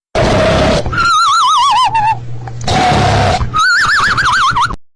Cartoon Funny Snoring Sound Effect Free Download
Cartoon Funny Snoring